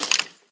sounds / mob / skeleton / step3.ogg